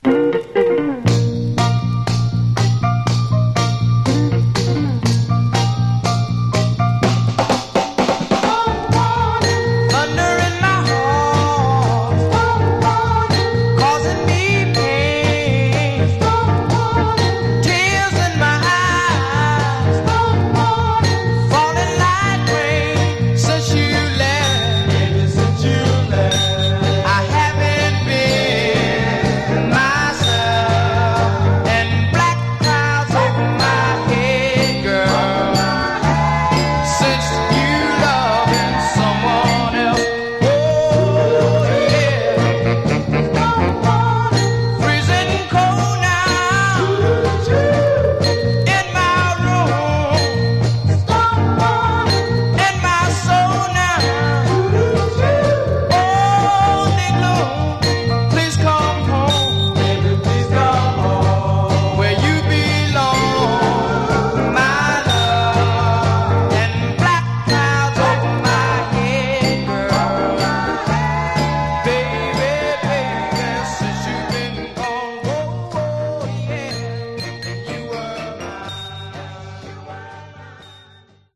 Genre: Northern Soul, Motown Style
Terrific Northern Soul dancer, with a Motown-style edge.